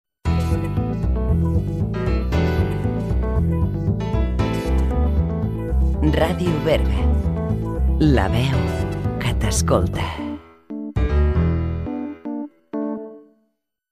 Indicatiu de nit